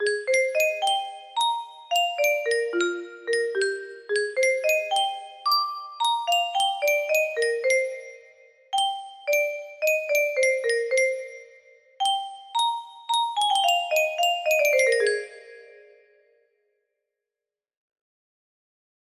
cold pancakes music box melody